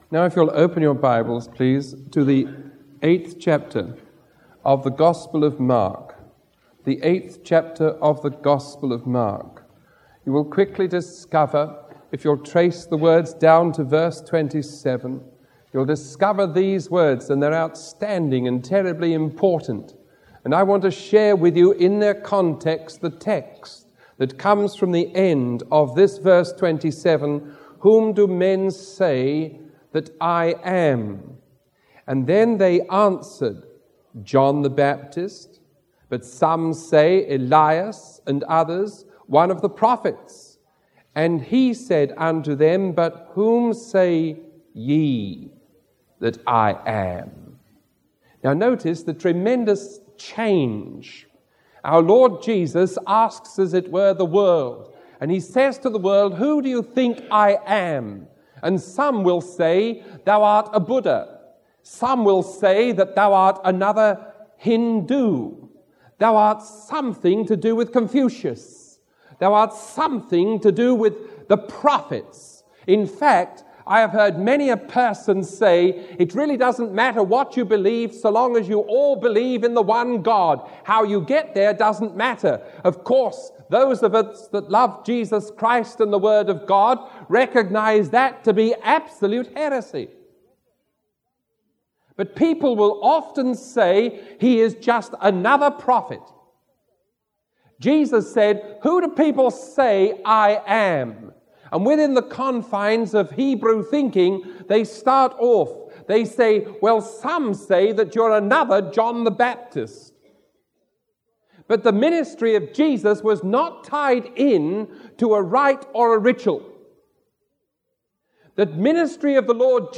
Sermon 0504A recorded on March 7